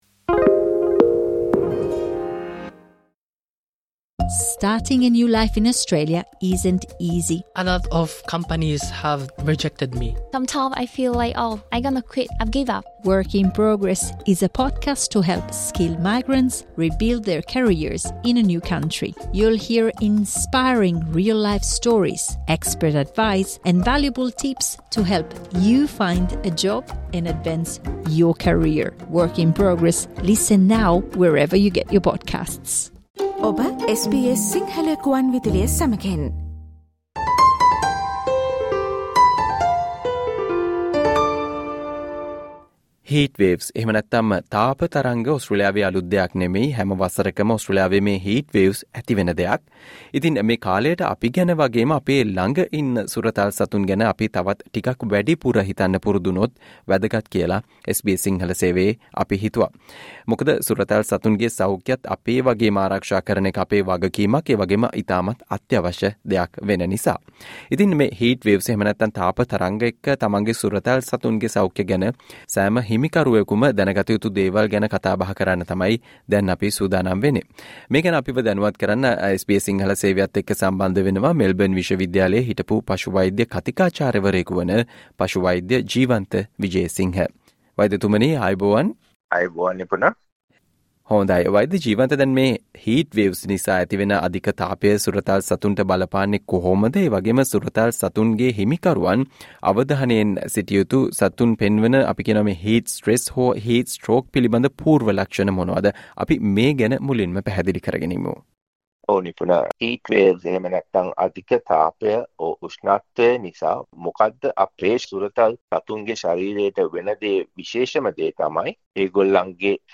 SBS සිංහල සේවය සිදු කල සාකච්චාව